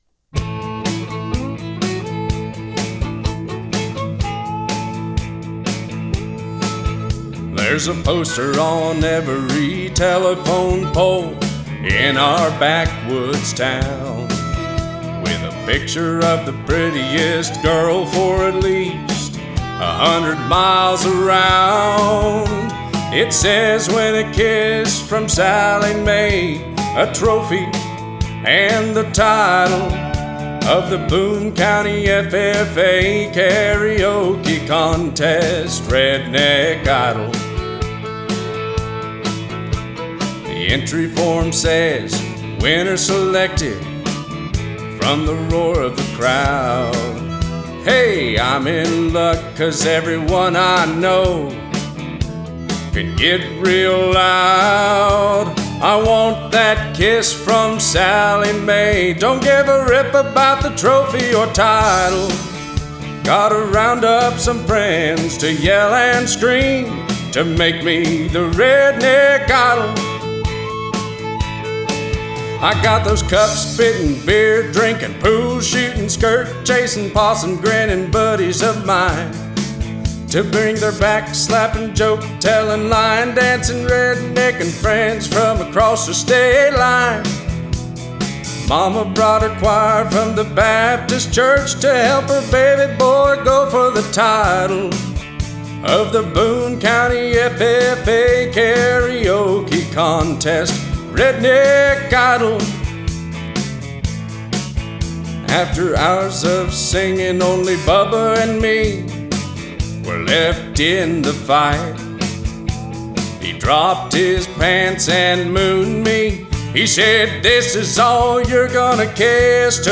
Male Country Songs
(Fast)